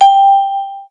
Alternative: Gelaber lauter :-( Die Lösung des Problems ein lauter Piepton. So kann s Gelaber leise sein und der Piep bleibt höhrbar Cancel Up 0 Down Reply Verify Answer Cancel 0 Former Member over 14 years ago Camera.wav Mein Piep für Blitzer Cancel Up 0 Down Reply Verify Answer Cancel
Camera.wav